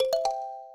kalimba_ceg.ogg